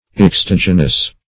Extogenous \Ex*tog"e*nous\, a.